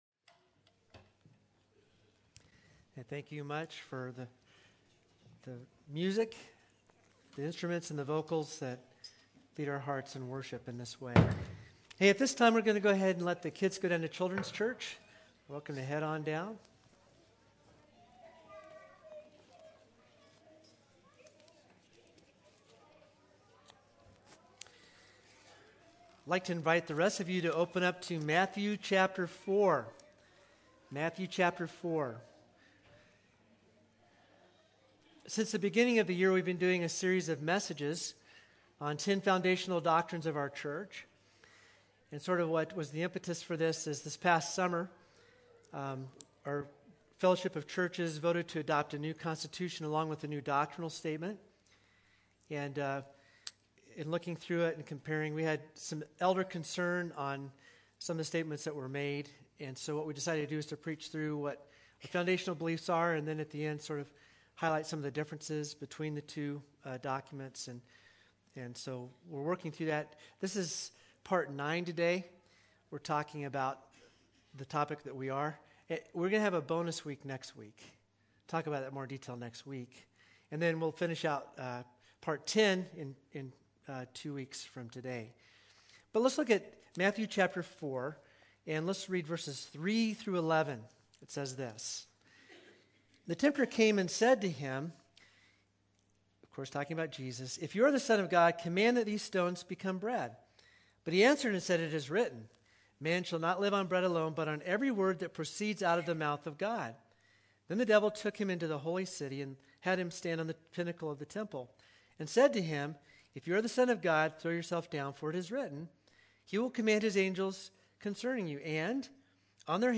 2_27-22-Sermon.mp3